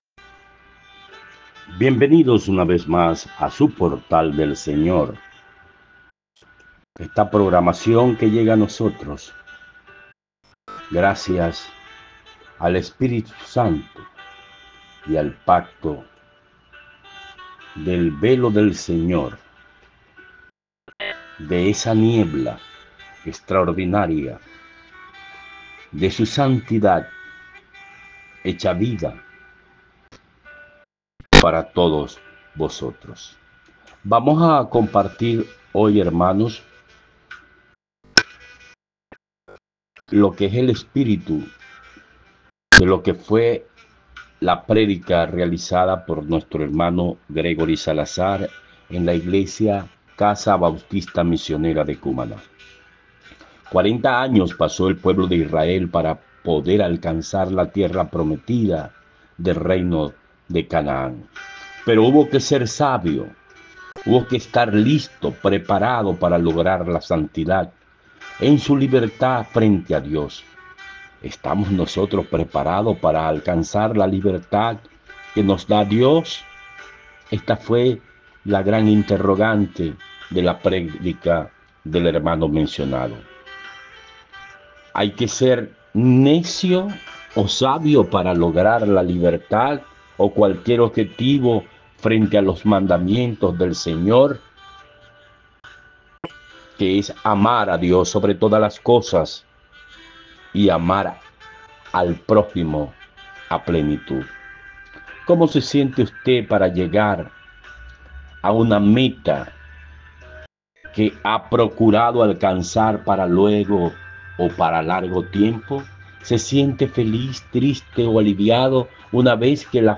Predicando